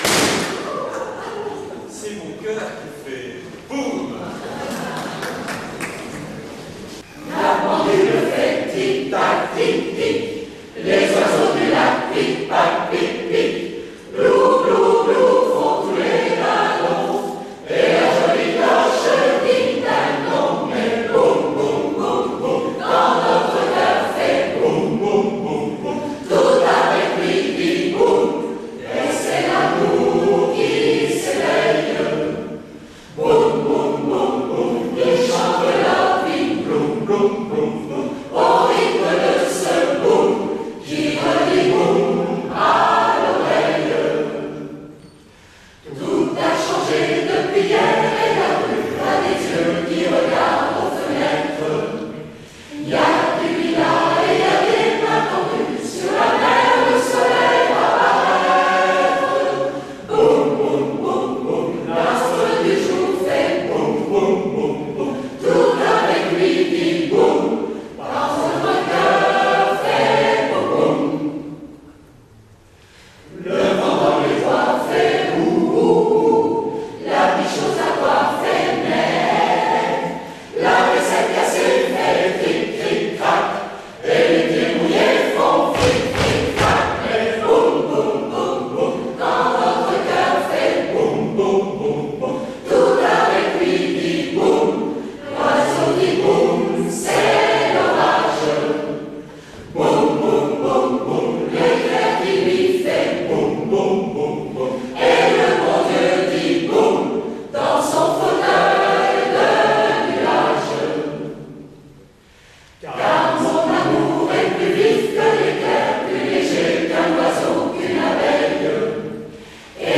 Boum concert bichoral.mp3